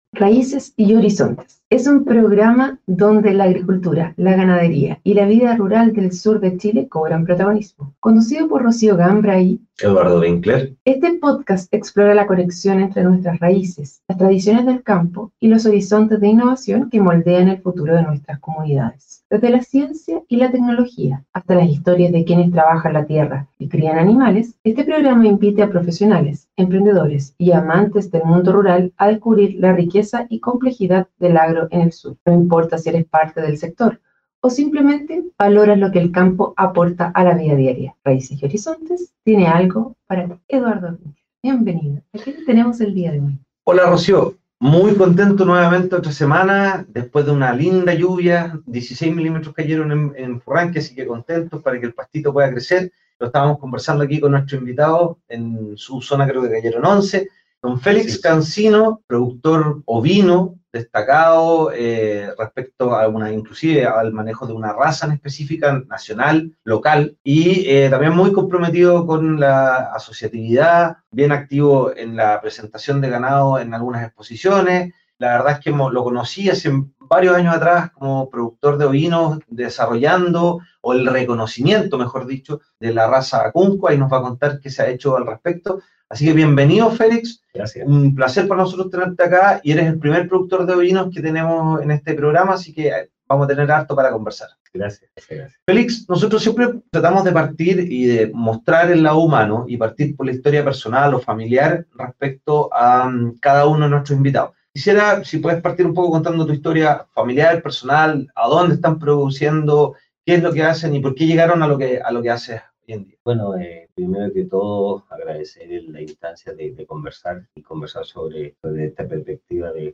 compartió su historia y visión sobre la ganadería ovina en el sur de Chile en una reciente entrevista en el programa "Raíces y Horizontes".